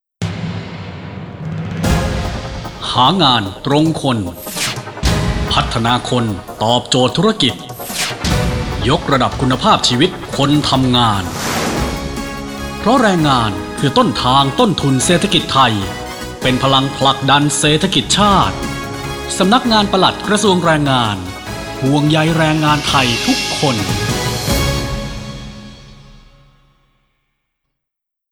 ดาวน์โหลดสปอตวิทยุ - กระทรวงแรงงาน
Finalmix_Spot_samnakngaanplad_krathrwngaerngngaan_V3.wav